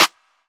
clap 12 (mira type).wav